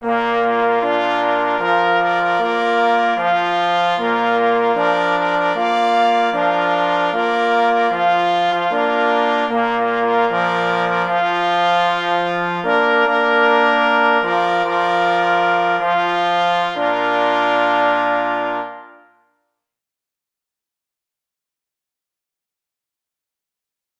muzyki do ceremoniału sztandarowego
Opis zasobu: fanfary opracowane przez Krzysztofa M. Kadleca na 2 plesy i 2 parforsy Tytuł utworu: Fanfary na wprowadzenie i wyprowadzenie pocztów sztandarowych Kompozytor: Jan Rentflejsz […]